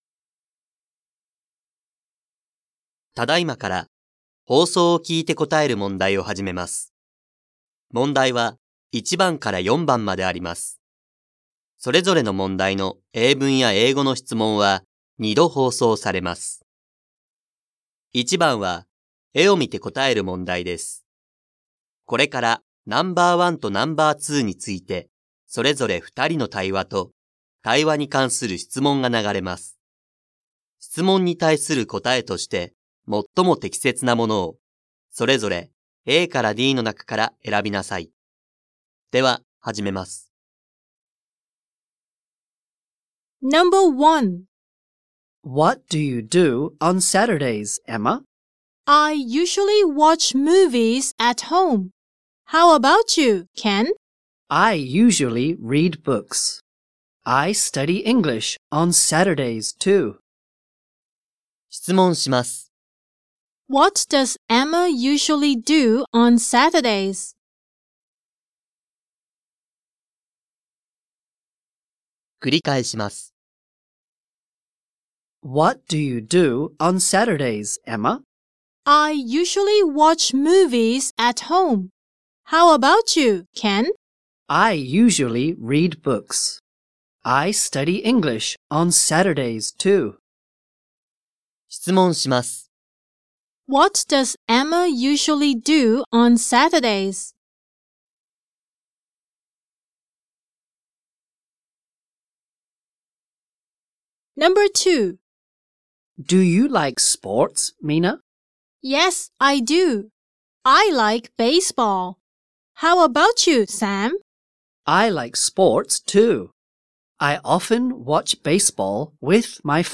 2024年度１年２号英語のリスニングテストの音声